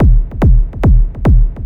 Kick 144-BPM.wav